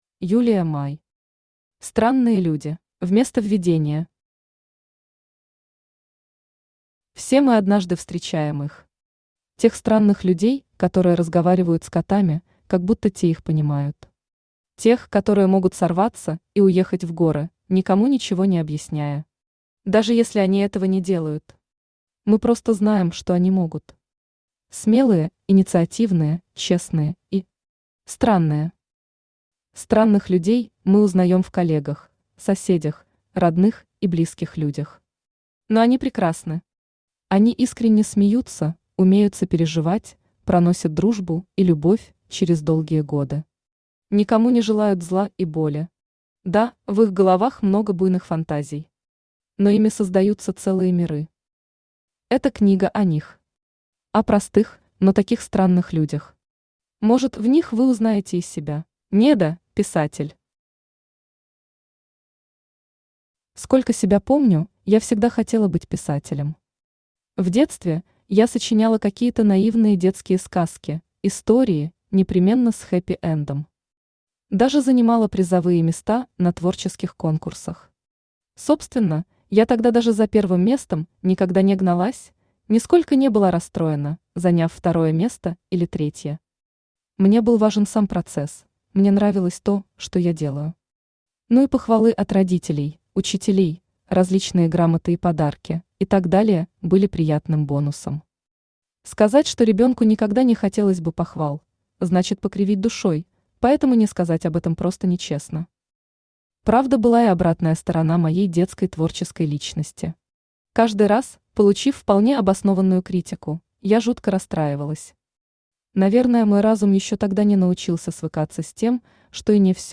Аудиокнига Странные люди | Библиотека аудиокниг
Aудиокнига Странные люди Автор Юлия Май Читает аудиокнигу Авточтец ЛитРес.